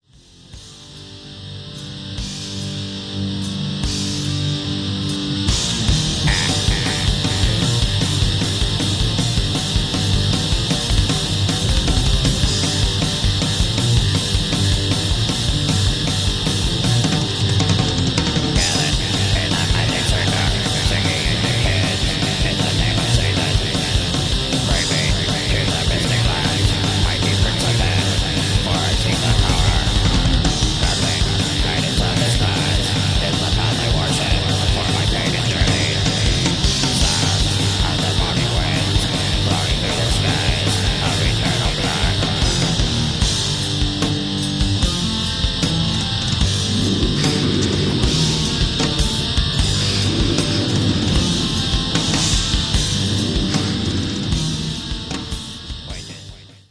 Mystical violence. Black Metal atack!.